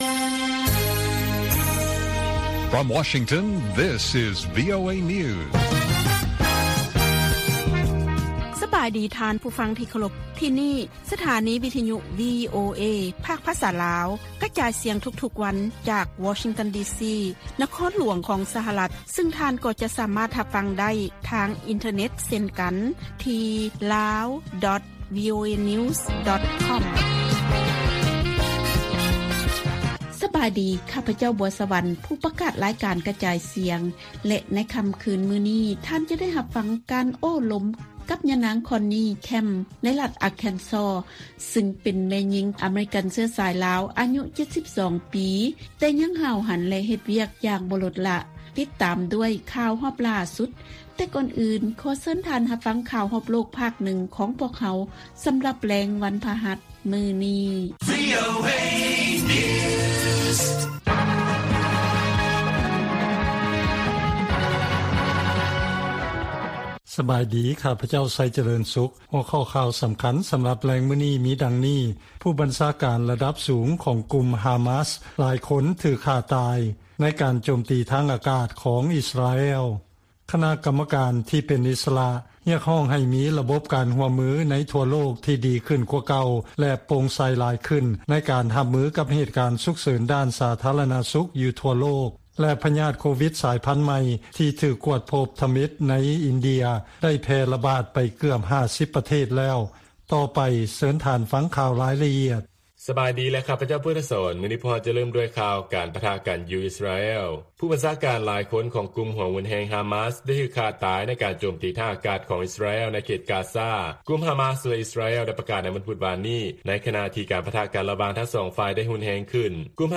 ລາຍການກະຈາຍສຽງຂອງວີໂອເອ ລາວ: ຄະນະກຳມະການ ທີ່ເປັນອິດສະຫຼະ ກ່າວວ່າ ໂຣກລະບາດໄວຣັສໂຄໂຣນາ ແມ່ນໄພຫາຍະນະ ທີ່ ‘ສາມາດປ້ອງກັນໄດ້’